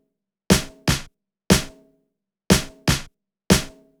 31 Snare.wav